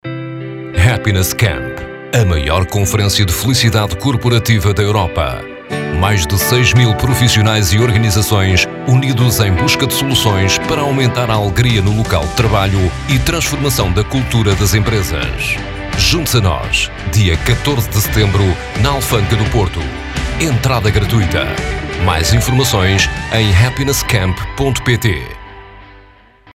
Portugese voice-over
Commercieel